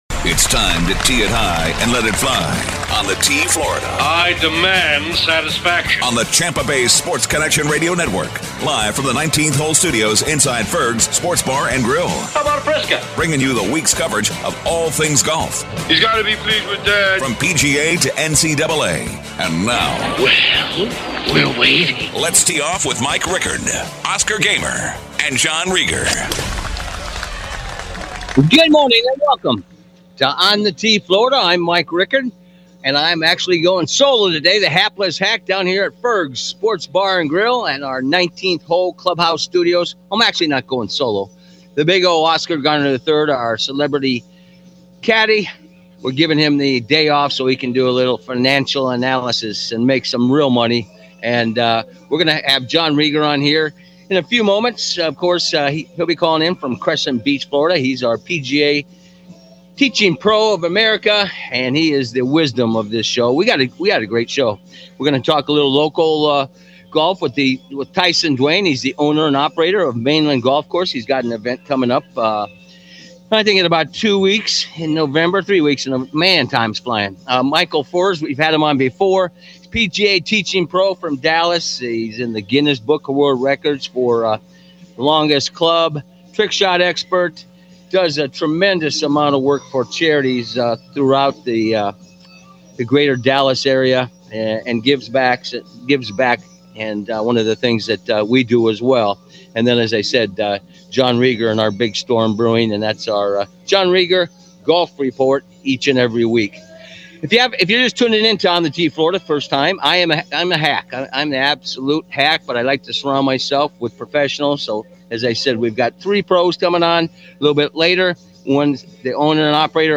"On the Tee Florida" 10-22-22 Airs live from Ferg's Saturdays at 11:05am